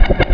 ui_changeCharacter.wav